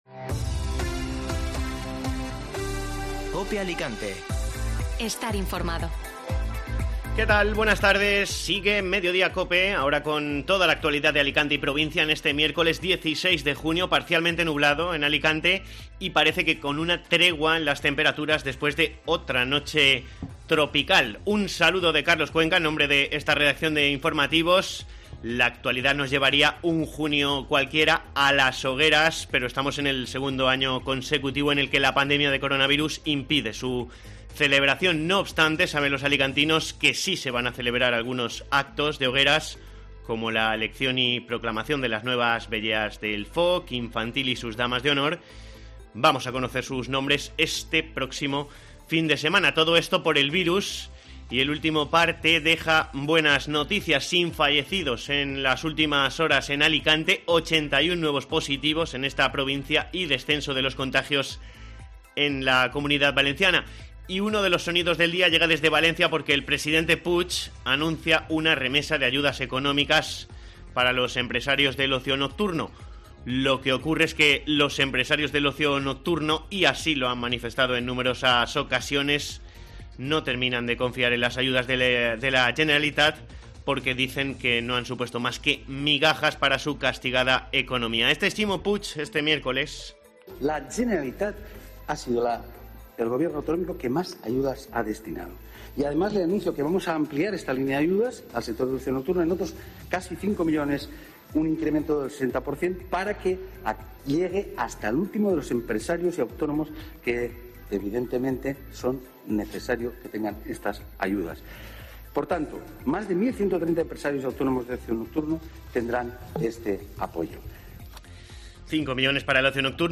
Informativo Mediodía COPE (Miércoles 16 de junio)